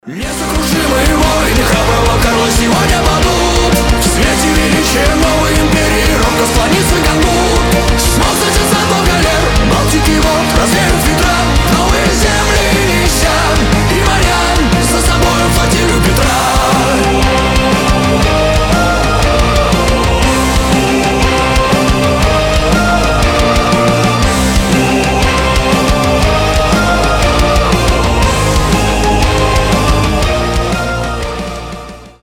рок
русскиеheavy metal